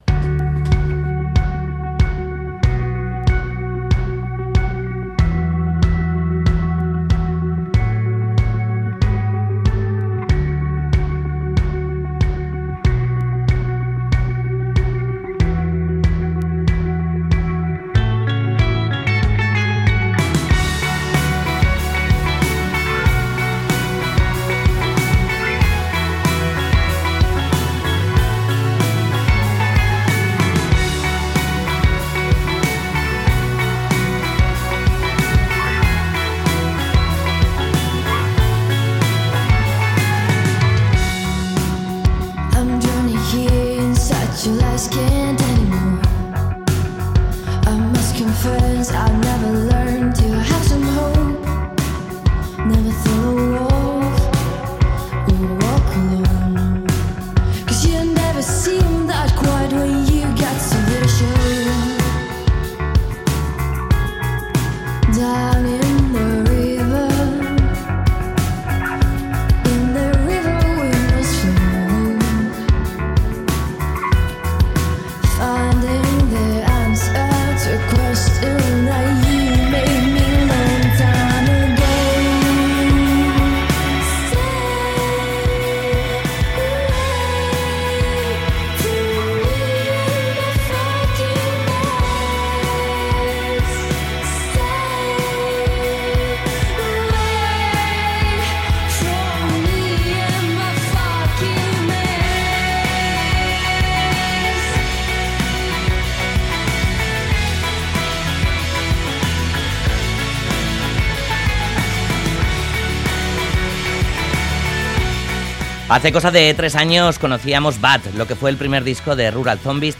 Audio: La banda de Zestoa Rural Zombies ha presentado en entrevista de Graffiti Radio Euskadi su nuevo disco titulado "From Home to Hospital St"